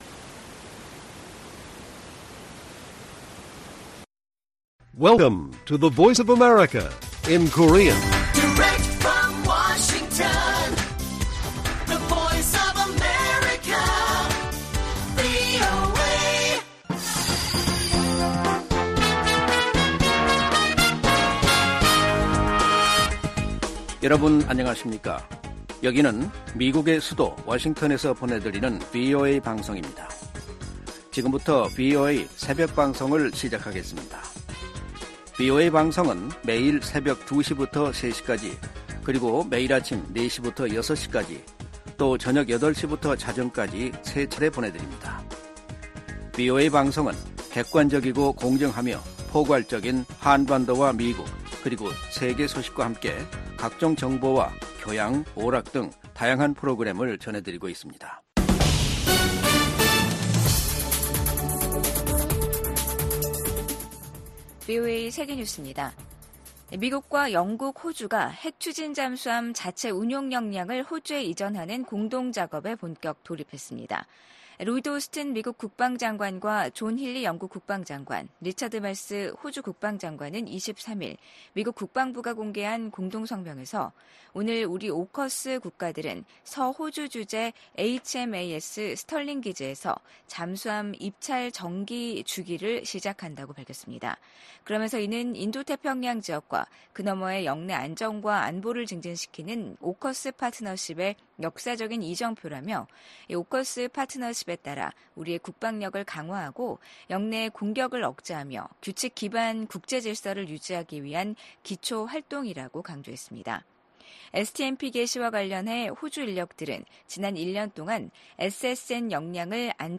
VOA 한국어 '출발 뉴스 쇼', 2024년 8월 24일 방송입니다. 카멀라 해리스 미국 부통령이 민주당의 대선 후보 수락 연설에서 모든 미국인을 위한 대통령이 되겠다며 분열된 미국을 하나로 통합하겠다는 의지를 밝혔습니다. 북한과 중국, 러시아가 인접한 중국 측 지대에 길이 나고 구조물이 들어섰습니다. 김정은 국무위원장 집권 후 엘리트층 탈북이 크게 늘어난 것으로 나타났습니다.